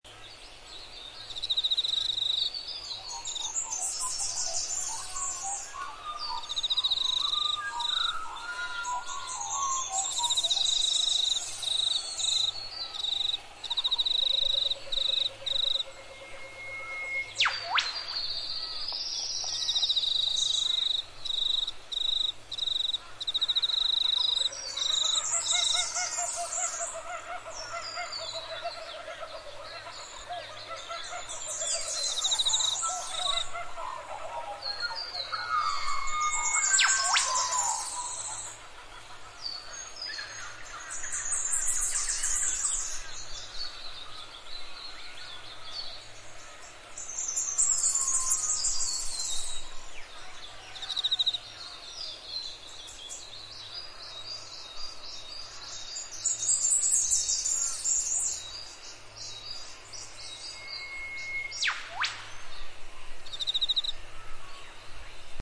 Kookaburra 71544 Mp 3